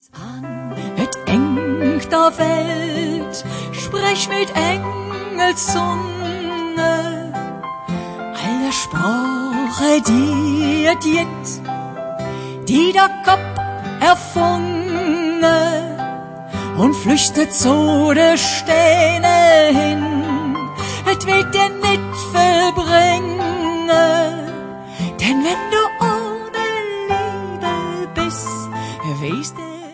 Weihnachtsleedcher und Verzällcher in Kölscher Mundart